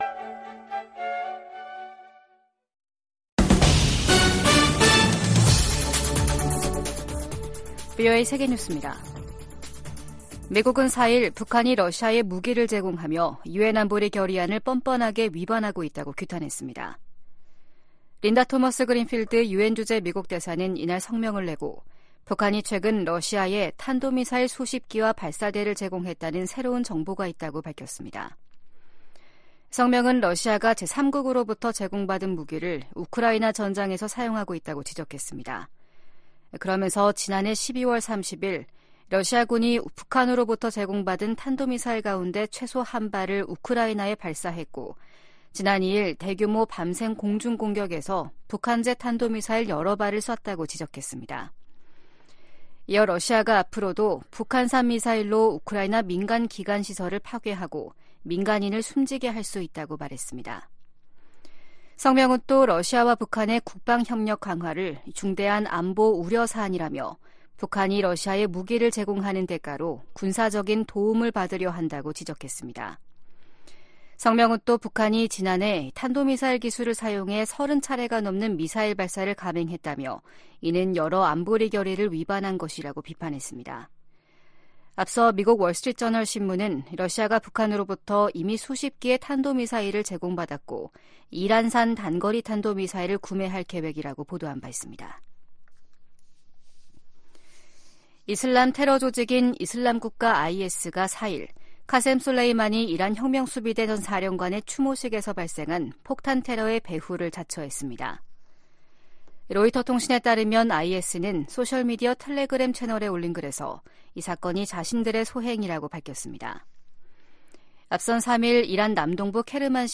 VOA 한국어 아침 뉴스 프로그램 '워싱턴 뉴스 광장' 2024년 1월 5일 방송입니다. 미국은 북한과의 대화에 큰 기대는 않지만 여전히 환영할 것이라고 국무부 대변인이 말했습니다. 김정은 북한 국무위원장이 남북관계를 '적대적 두 국가 관계'로 선언한 이후 북한은 대남노선의 전환을 시사하는 조치들에 나섰습니다. 23일로 예정된 중국에 대한 유엔의 보편적 정례인권검토(UPR)를 앞두고 탈북민 강제북송 중단 압박이 커지고 있습니다.